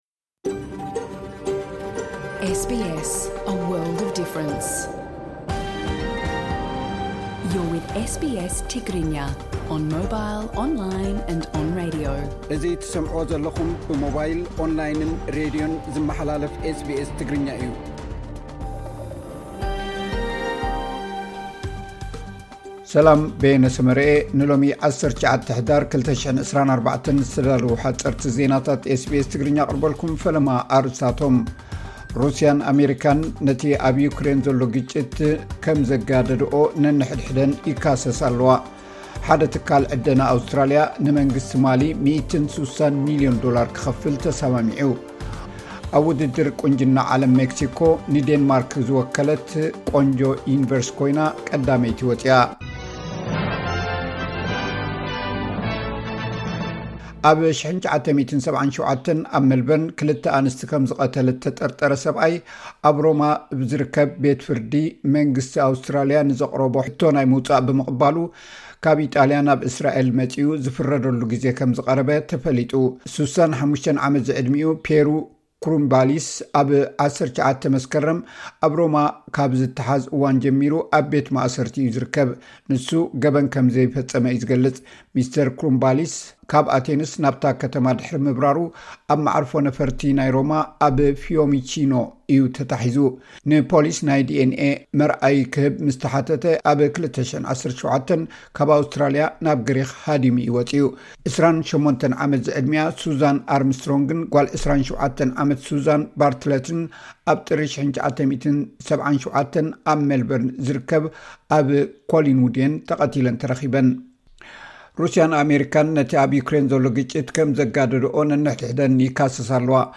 ዕለትዊ ዜናታት ኤስ ቢ ኤስ ትግርኛ (19 ሕዳር 2024)